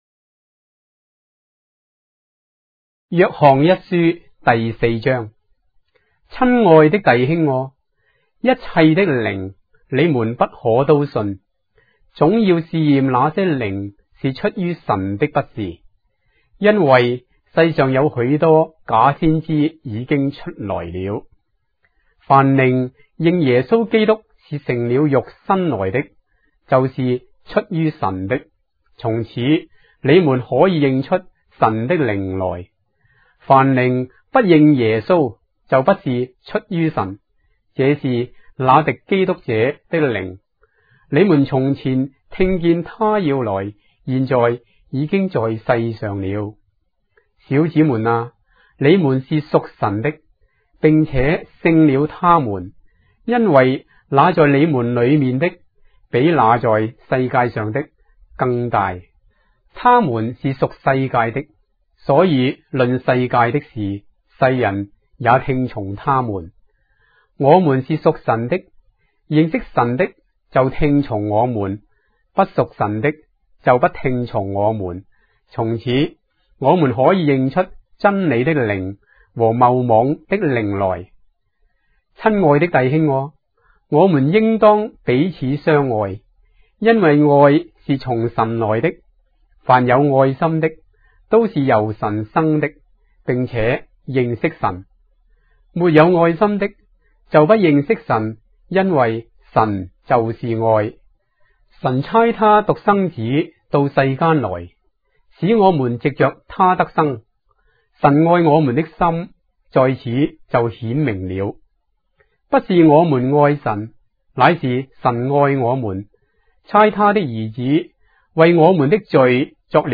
章的聖經在中國的語言，音頻旁白- 1 John, chapter 4 of the Holy Bible in Traditional Chinese